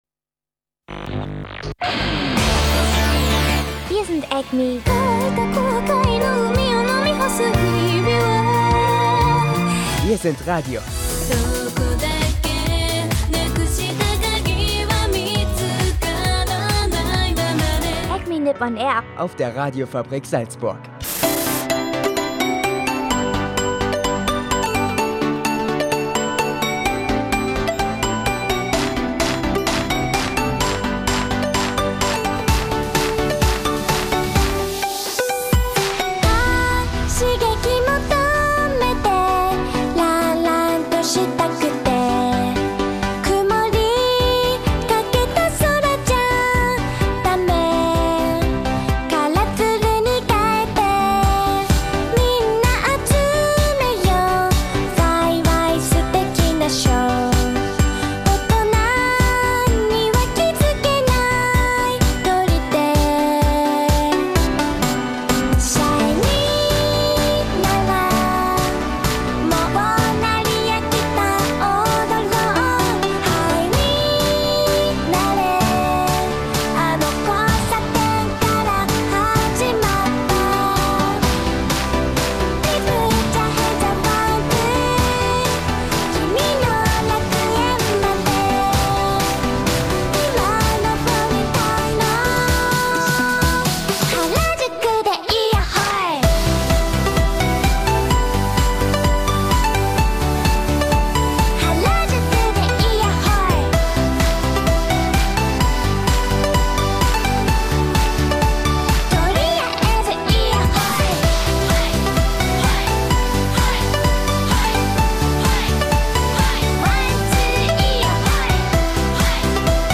Dazu gibt es natürlich Anime-News und Japan-News und viel Musik, darunter auch solche, die man wohl ansonsten höchstens auf /a/ hören würde.